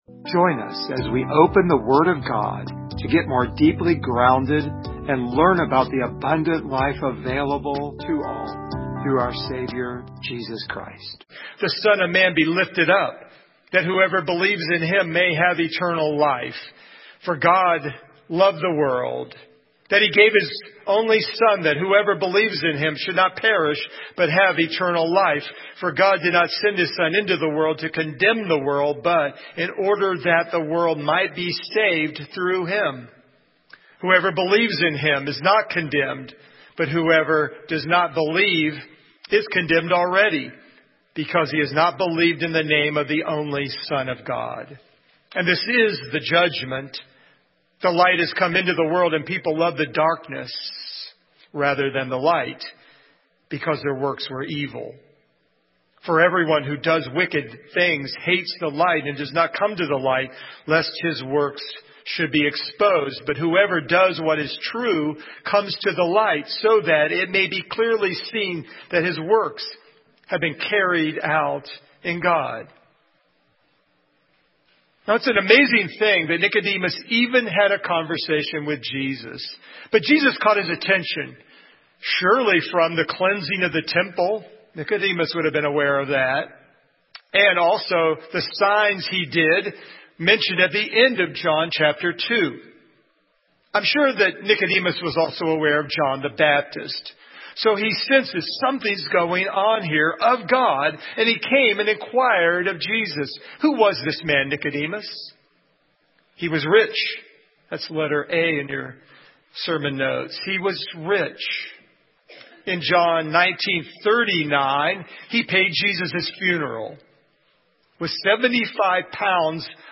Service Type: Sunday Morning
Download Files Notes Topics: Born Again , Salvation share this sermon « Jesus Cleans House!